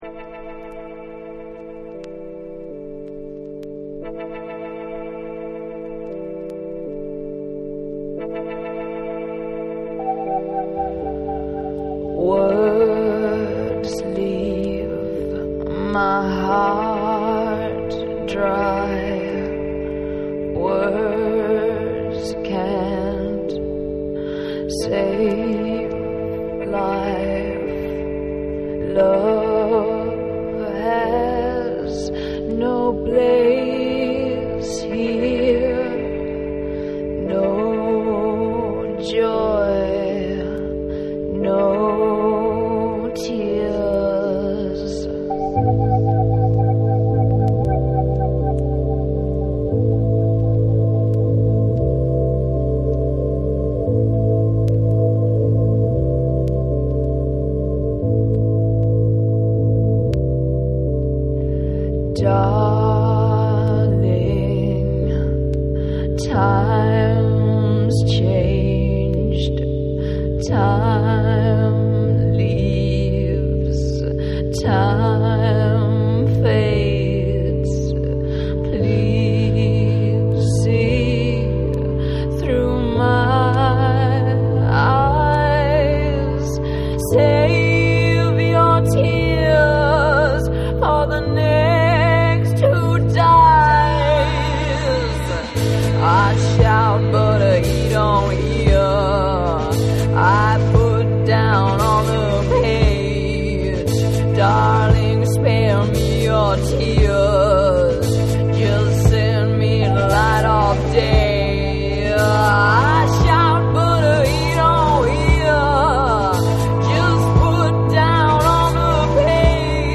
アコースティック・ギター中心のシンプルな構成ながらも存在感のあるヴォーカルが耳を引く1。
NEW WAVE & ROCK